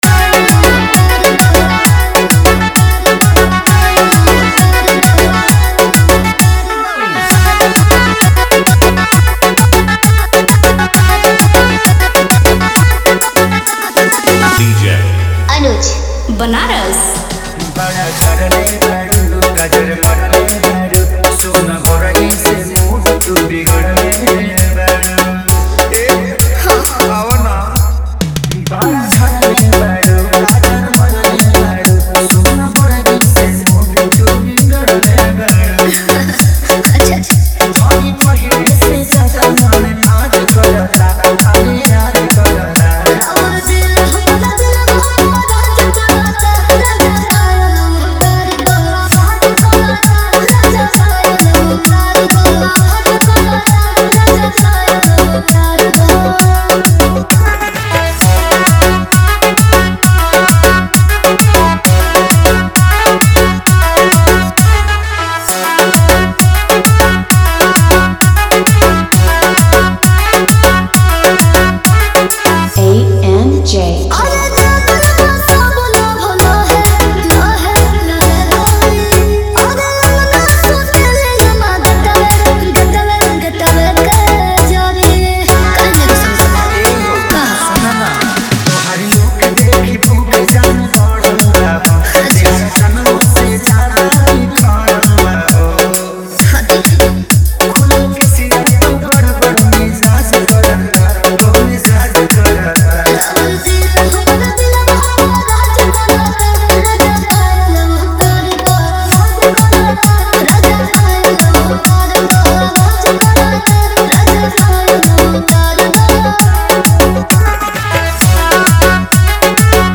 न्यू भोजपुरी डीजे सांग
शैली (Genre): भोजपुरी डांस मिक्स
⌛ ड्यूरेशन: फुल डीजे वर्जन